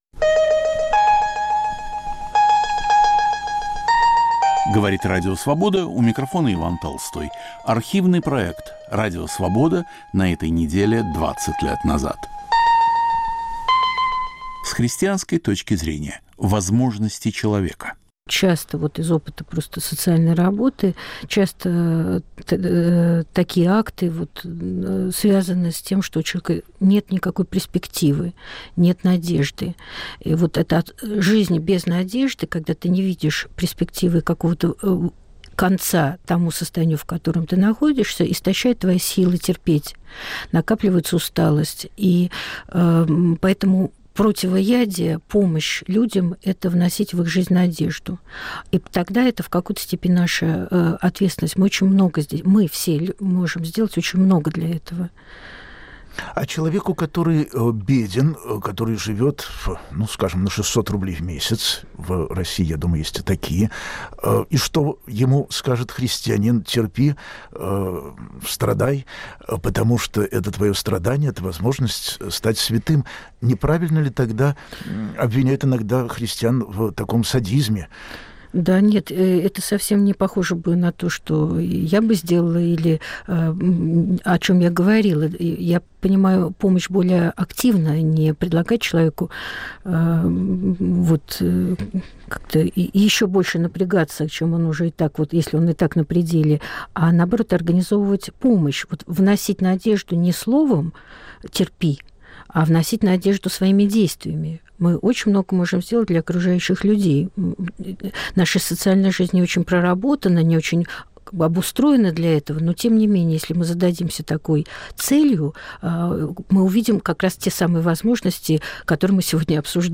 Религия и реальность. Помощь окружающим людям. Автор и ведущий Яков Кротов.